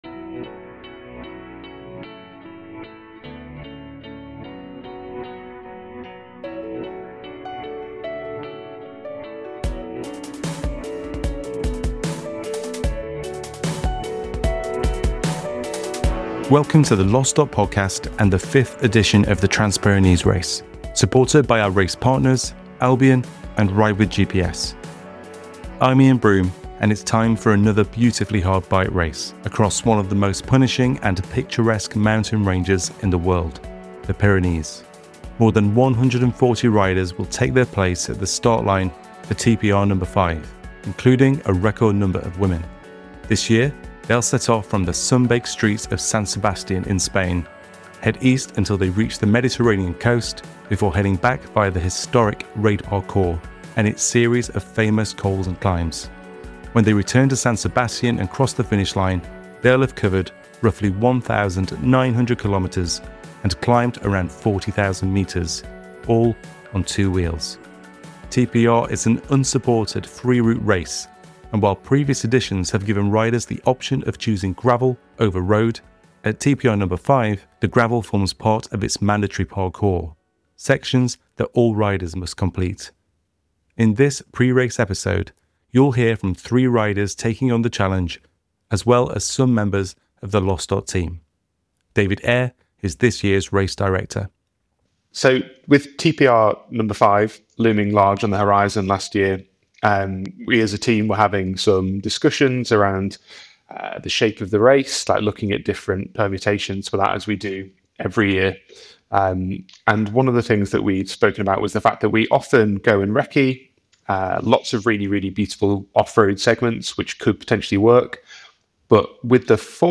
Tune in for a pre-race episode to whet your appetite for aggregate. Race Organisers unpack the route ahead, and interviews with a selection of riders help set the stage for this off-road adventure.